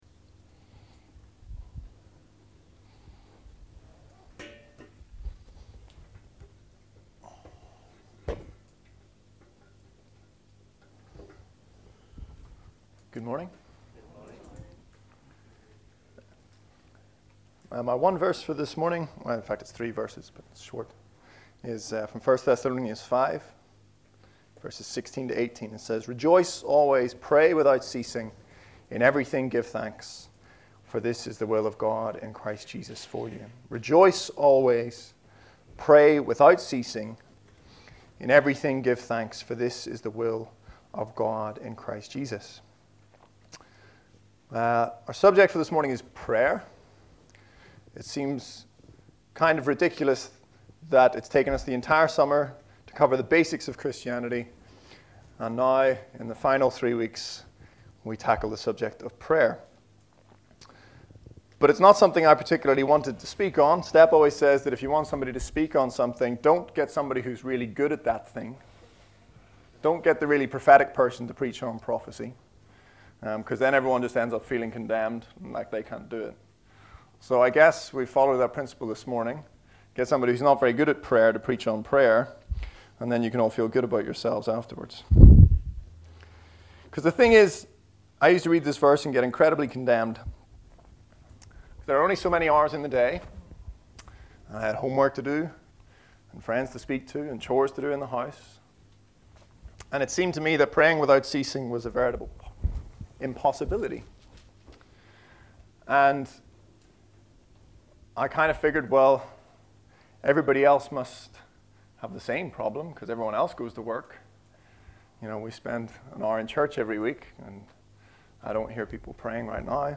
Message: “Prayers”